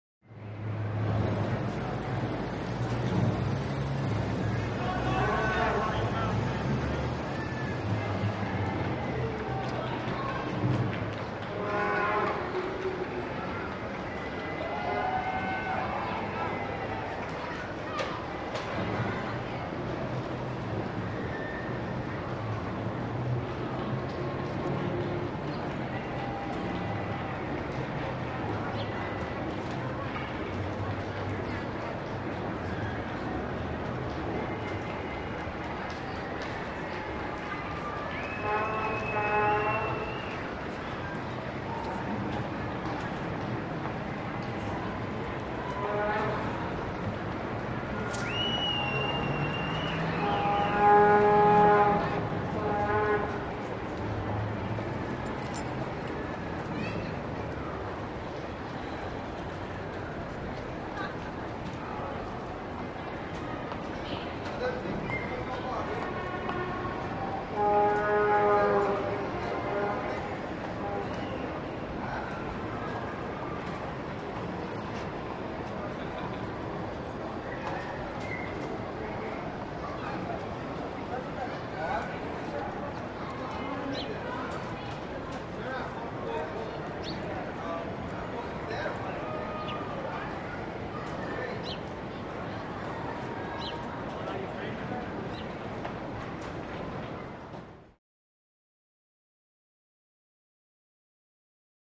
Crowd Dispersing, Near Stadium Entrance And Parking Lot. Medium To Distant Reverberated Walla With Air Horns And Footsteps. A Few Cars By Close, And Distant Traffic. Some Bird Chirps.